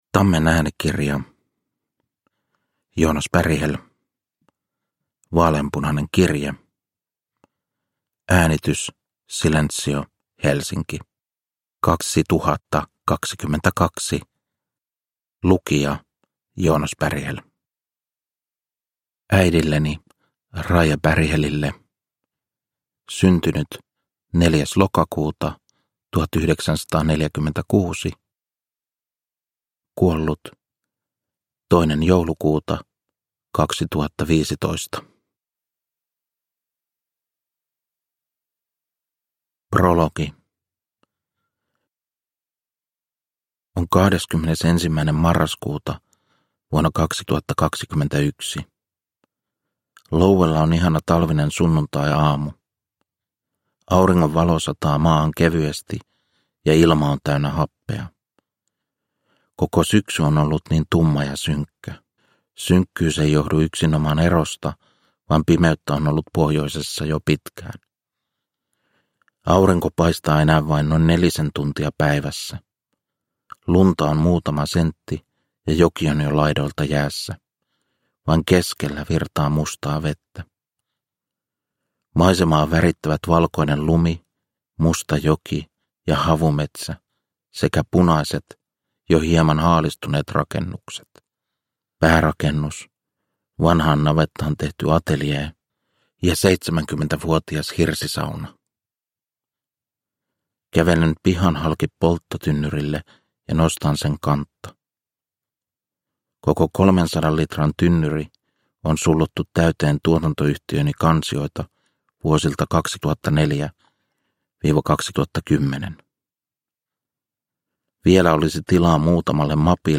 Vaaleanpunainen kirje – Ljudbok – Laddas ner
Uppläsare: Joonas Berghäll